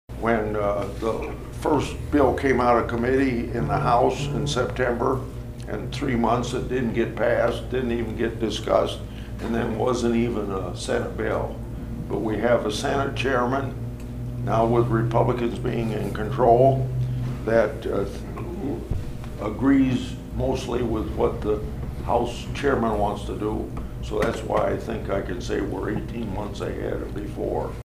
(Atlantic) Senator Chuck Grassley met with members of the Cass County Farm Bureau and others in the Ag community at TS Bank in Atlantic Friday afternoon.